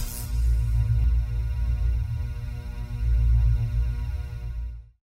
shieldRecharge.wav